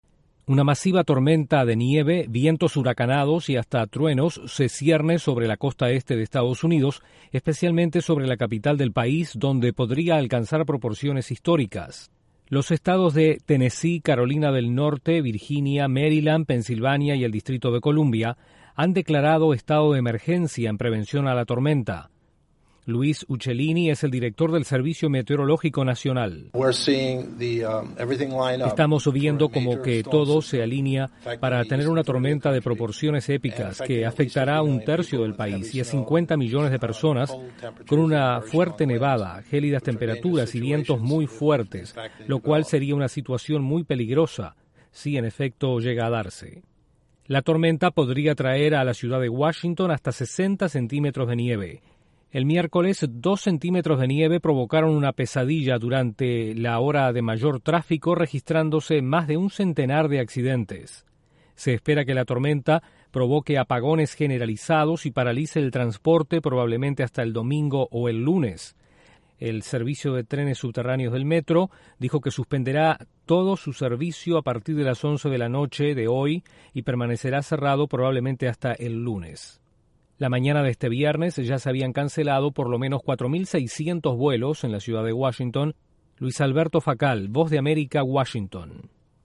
La costa Este de Estados Unidos aguarda la llegada de una tormenta invernal que podría paralizar la capital del país. Desde la Voz de América en Washington informa